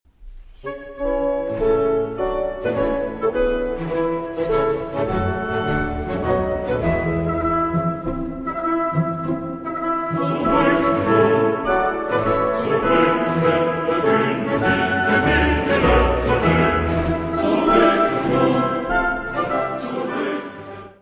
Área:  Música Clássica
für tenor solo, männerchor und orchester